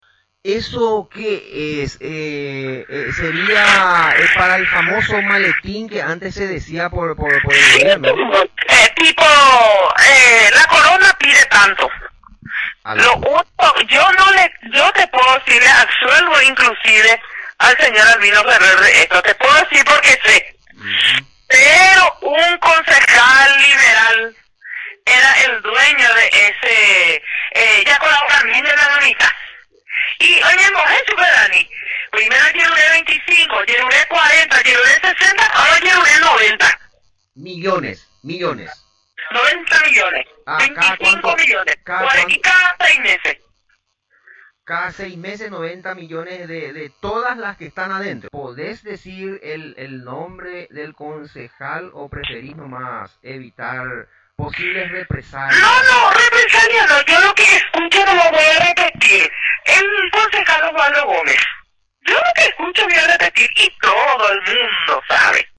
Parte de la entrevista